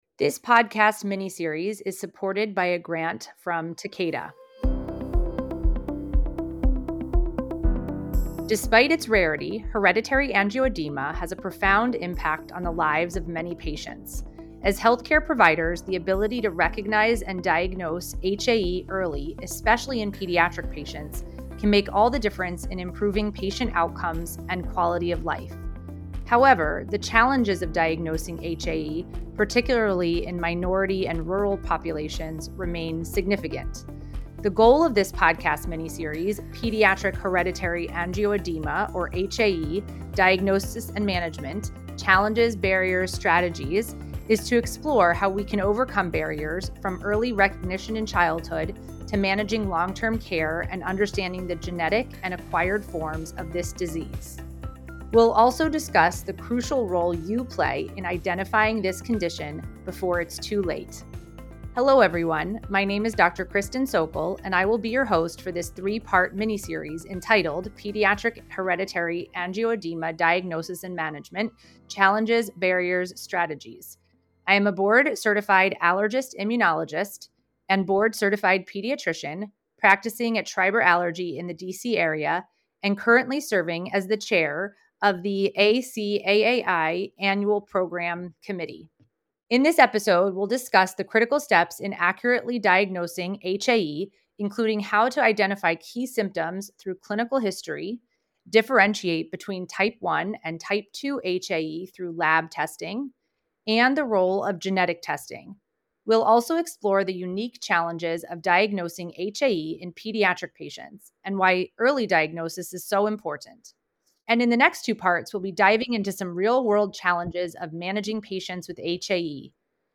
This discussion covers the key factors in accurately diagnosing HAE, including clinical history, lab testing, and the role of genetic testing in distinguishing between HAE types. Special considerations for diagnosing pediatric patients will also be discussed.